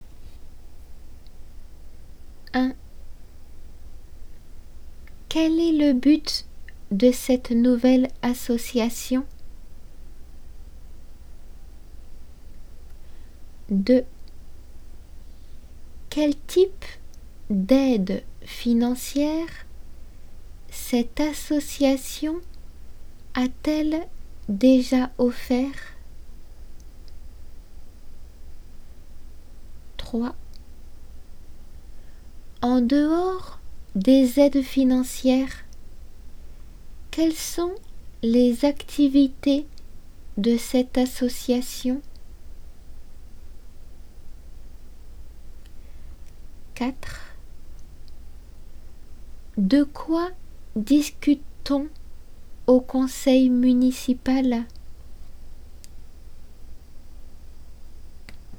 読まれる質問